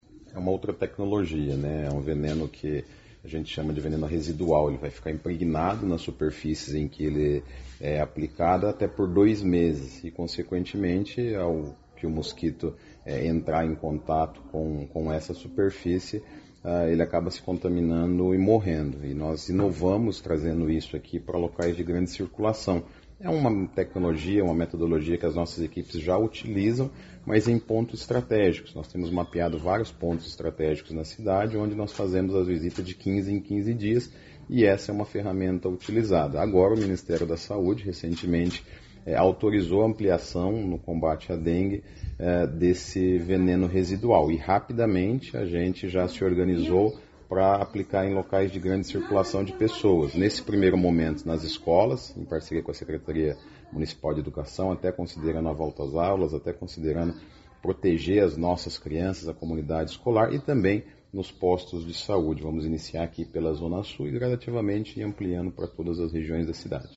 Na ocasião, o secretário de Saúde do município, Felippe Machado, falou sobre a aplicação com o produto especial e como ele funciona diante do Aedes aegypti – mosquito causador da dengue, chikungunya e zika.